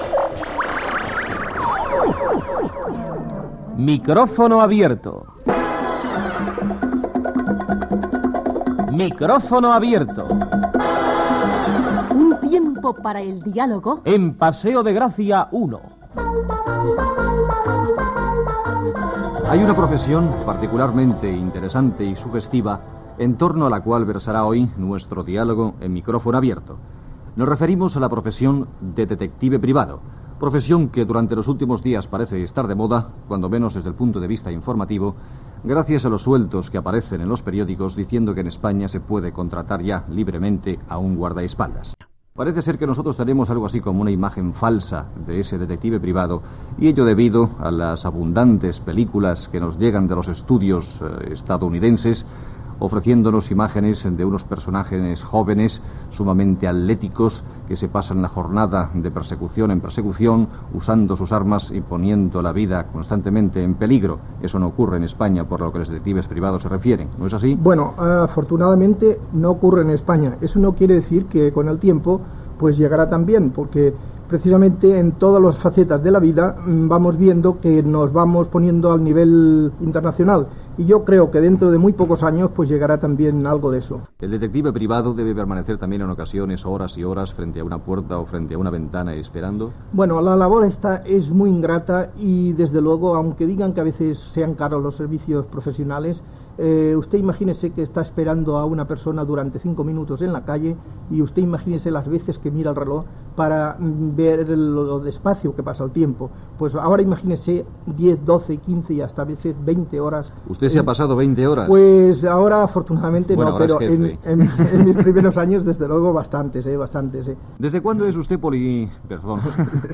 Careta del programa
presentació del tema i entrevista a un detectiu privat
Entreteniment